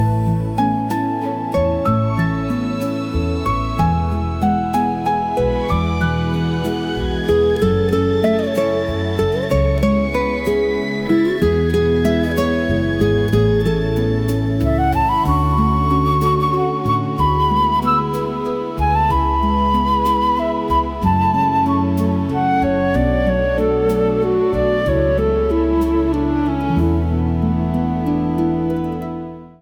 (Vintage Soul Instrumental)